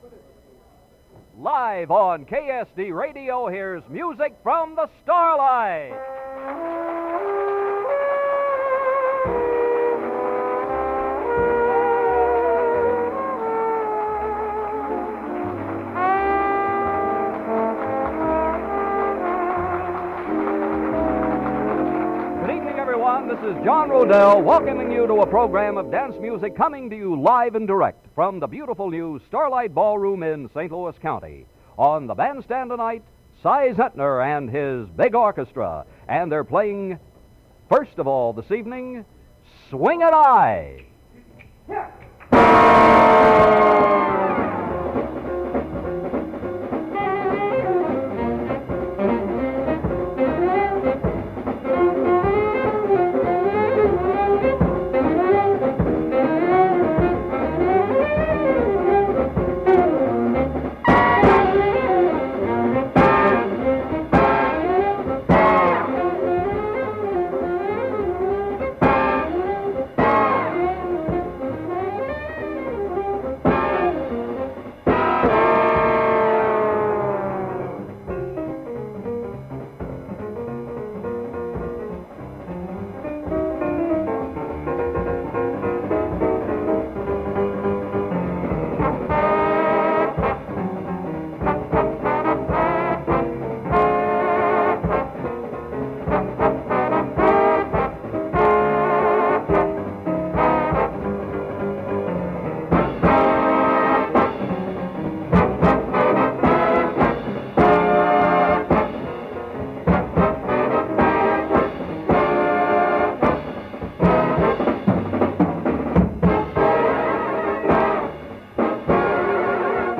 Original Format aircheck
big band